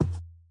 Minecraft Version Minecraft Version snapshot Latest Release | Latest Snapshot snapshot / assets / minecraft / sounds / mob / camel / step4.ogg Compare With Compare With Latest Release | Latest Snapshot
step4.ogg